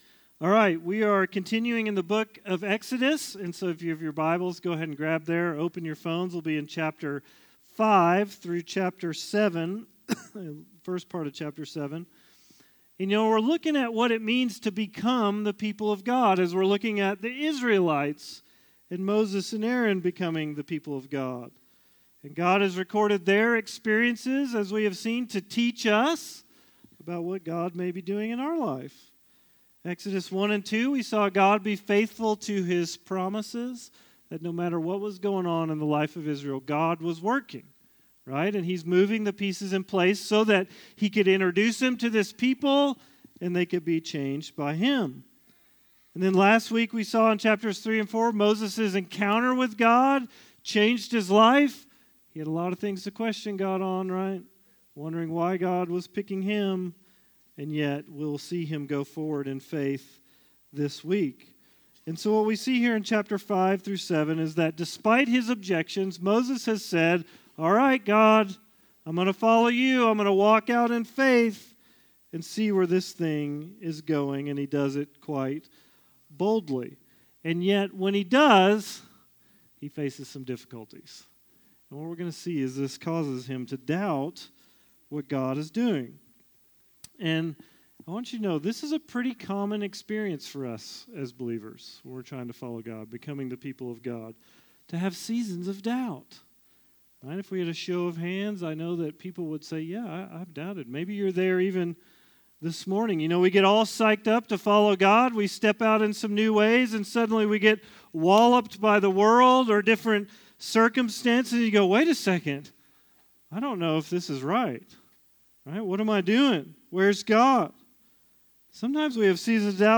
Message: "The 10th Commandment